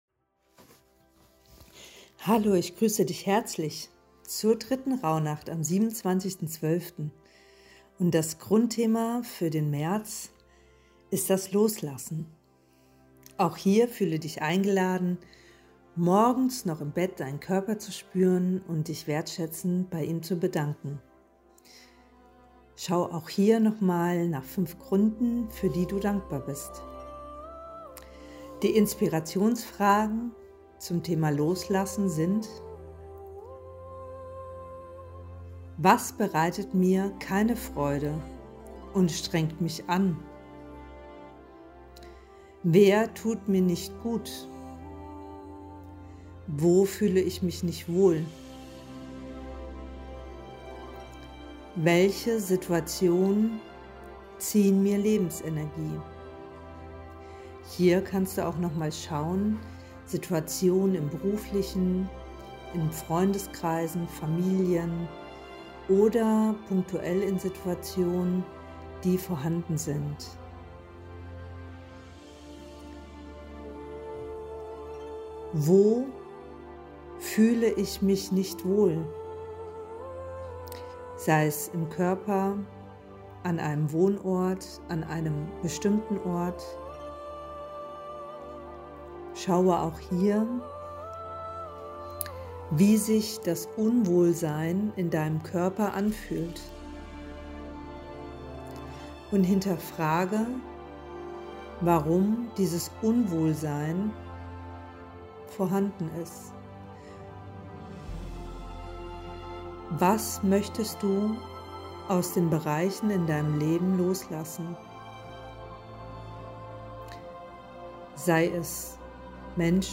Musik GEMAfrei: